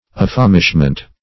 Affamishment \Af*fam"ish*ment\ (-ment), n.
affamishment.mp3